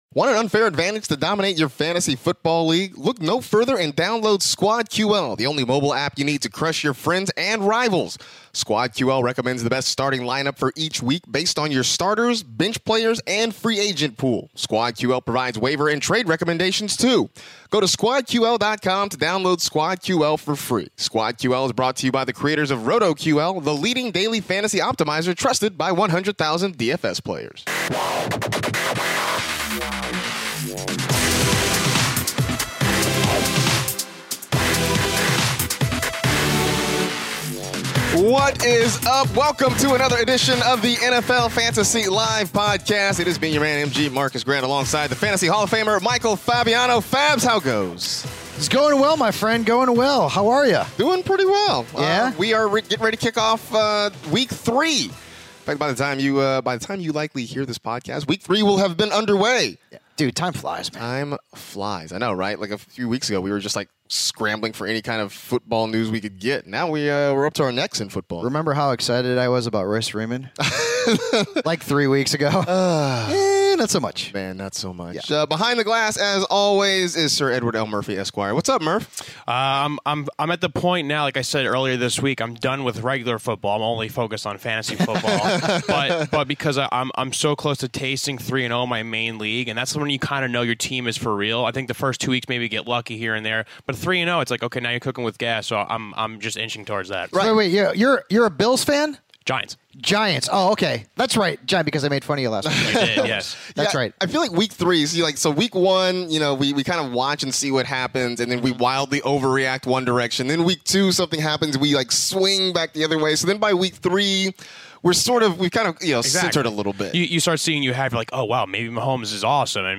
WWE Superstar and huge Chicago Bears fan, Seth Rollins, calls into the show to chat about how excited he is for the new look Bears and his WWE Fantasy League (15:55).